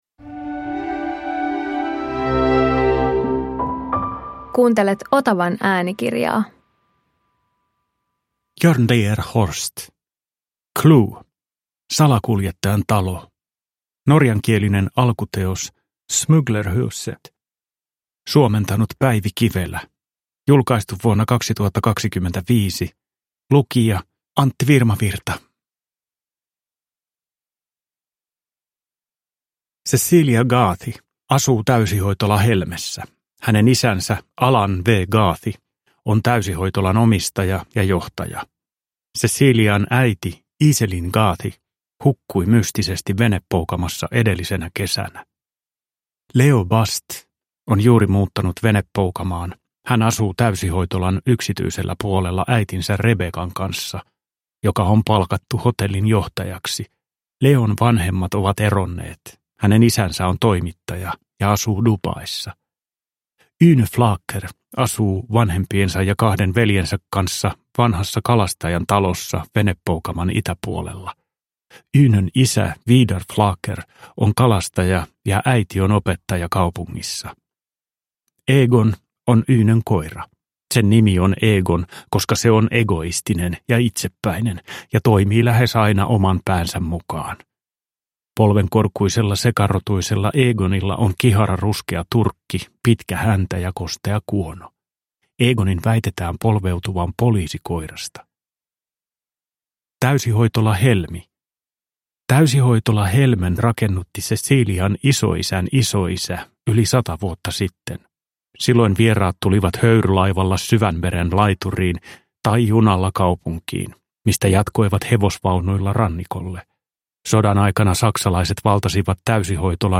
CLUE – Salakuljettajan talo – Ljudbok